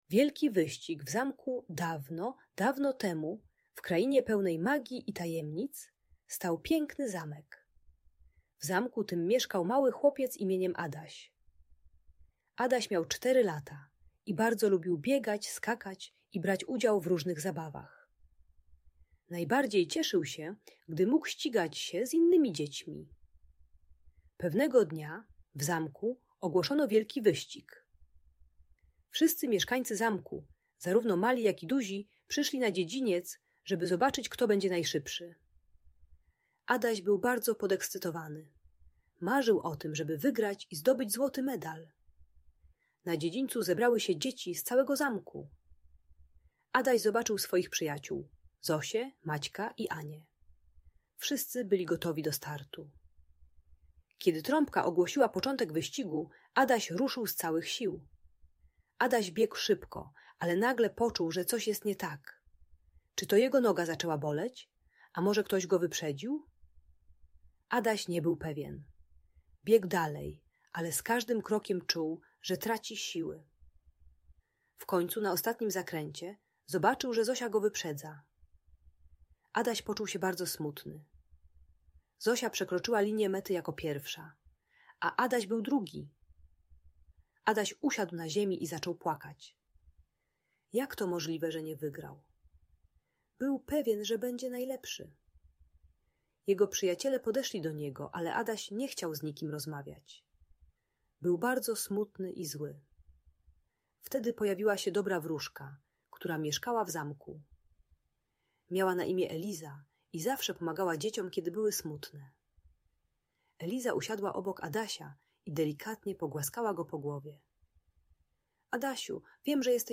Wielki Wyścig w Zamku - Audiobajka